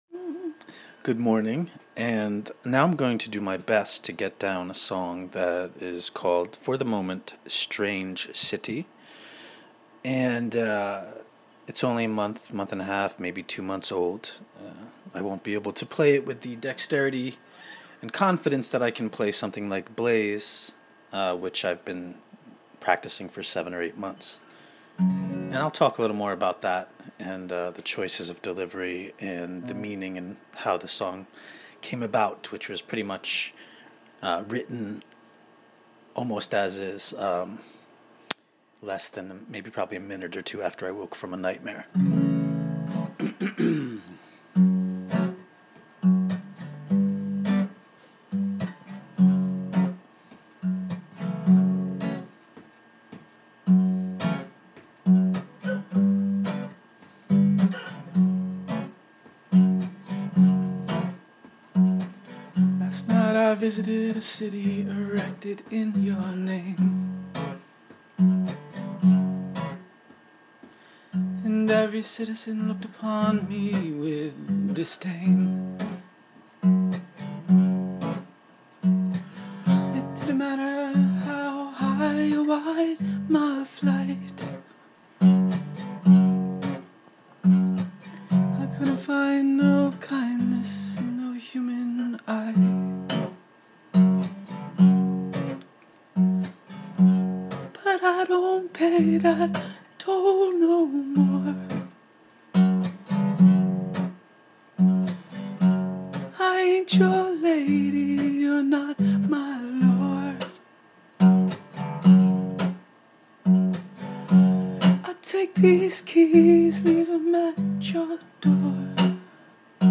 Moody, narrative, dreamscape remembrance.